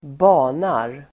Uttal: [²b'a:nar]